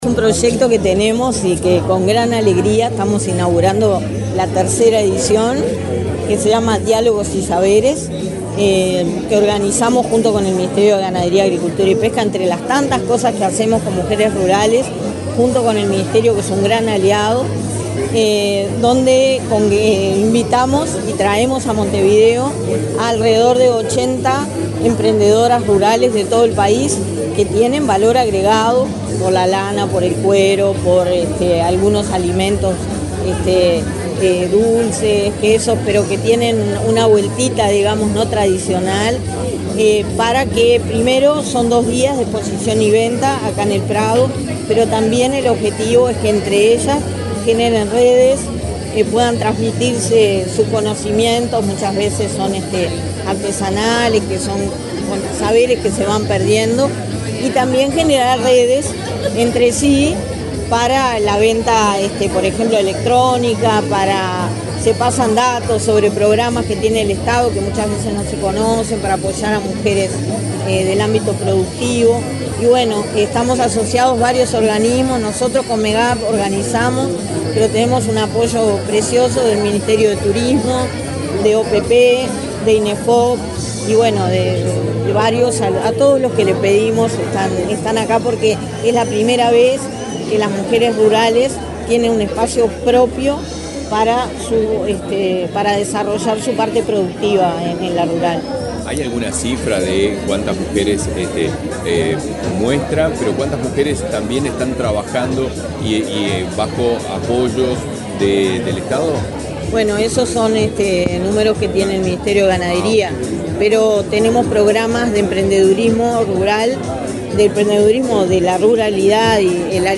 Declaraciones de la directora de Inmujeres, Mónica Bottero